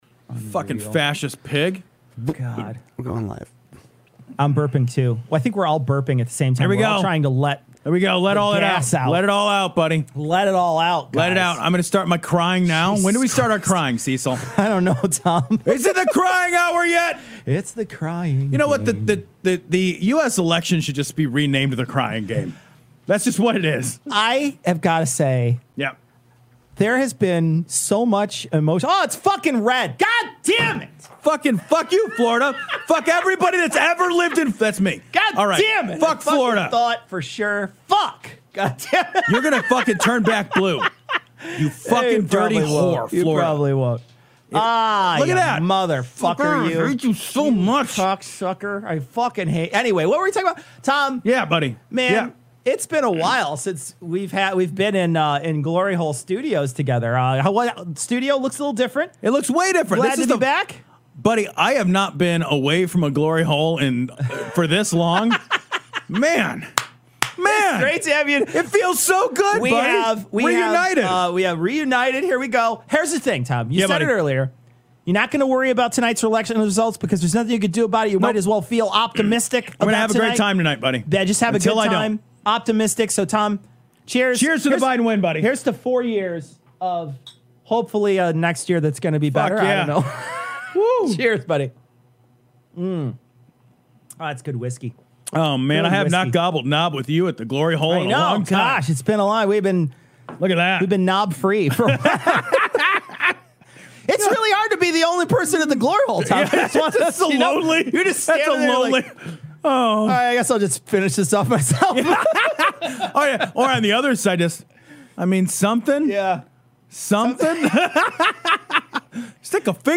livestream